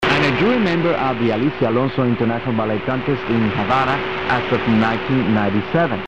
Shortwave DXing for Green River (the current active station) is done with a Grundig S350 and G8 Traveller II Digital radio set mostly to narrow bandwidth.